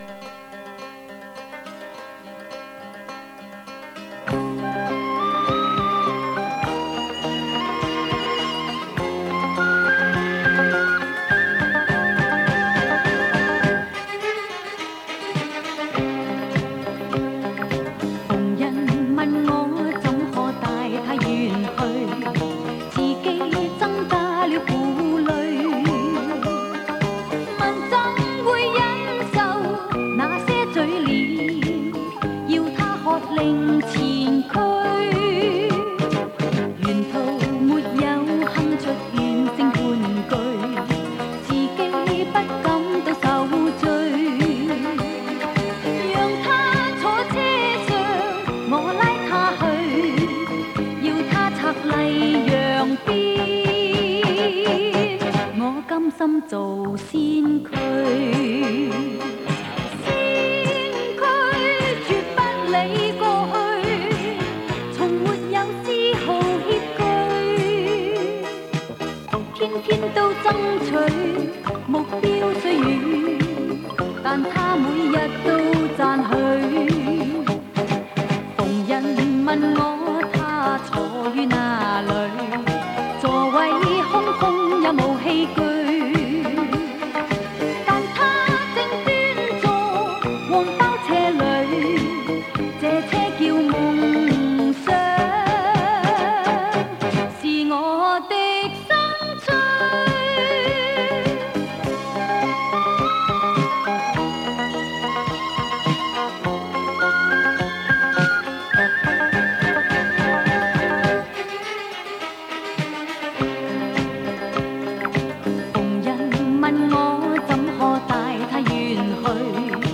磁带数字化：2022-06-11
嗓音出众，屡获“金嗓”美誉，又由于音域广阔，气量雄厚，获香港词人黄沾冠以“乐坛巨肺”之名。